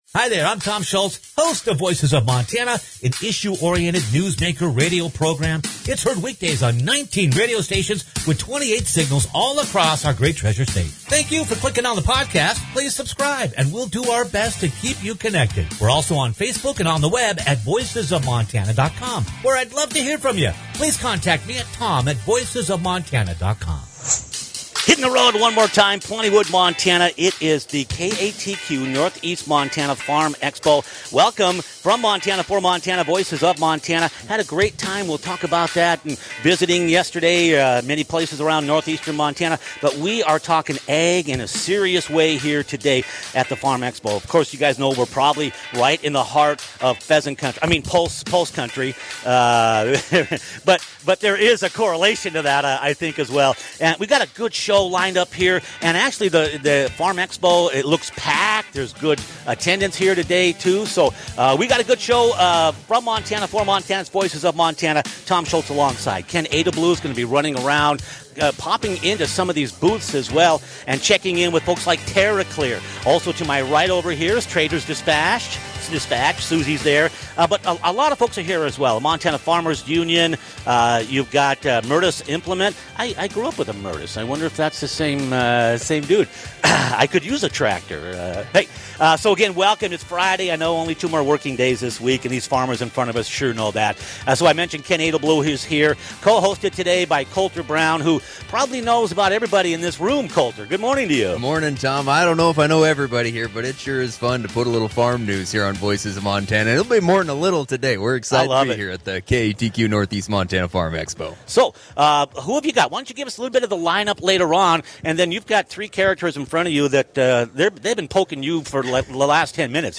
Broadcasting LIVE – KATQ North East Montana Farm Expo - Voices of Montana
broadcasting-live-katq-north-east-montana-farm-expo.mp3